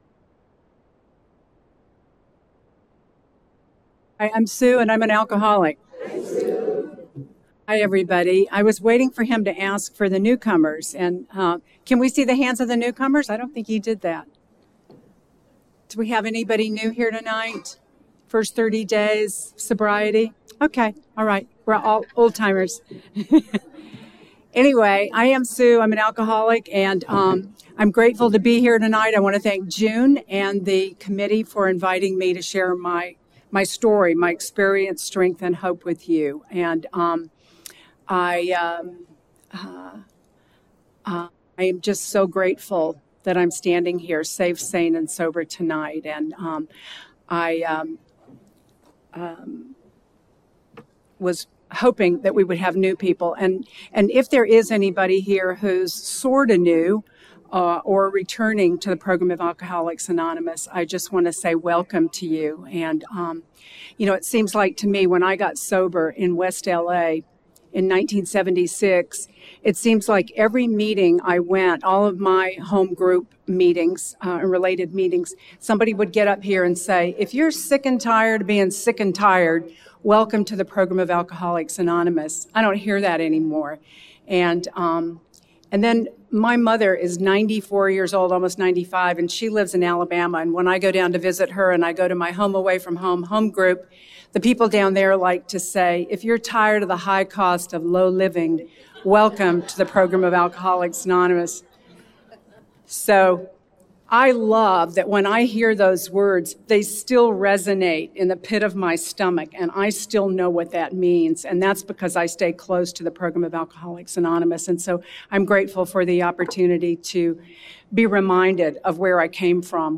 36th Annual Serenity By The Sea
Hometown Speaker &#8211